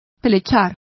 Complete with pronunciation of the translation of moult.